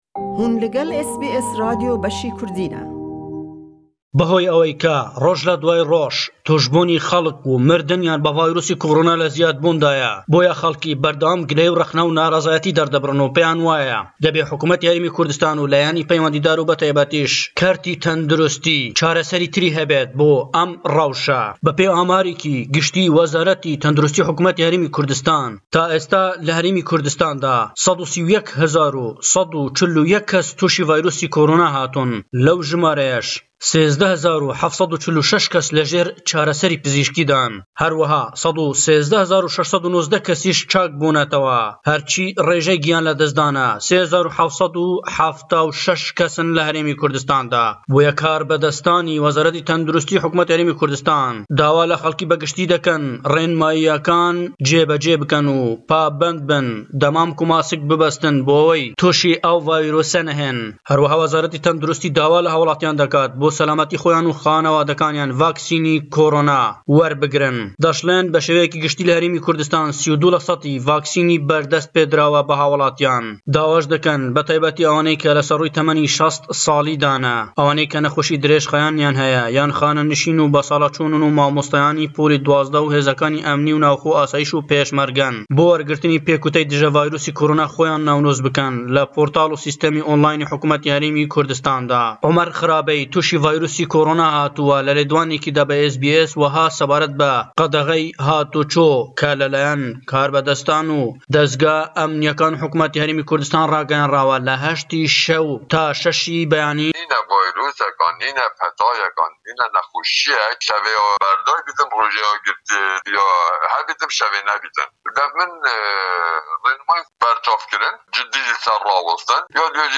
Le em raporte da le Hewlêre we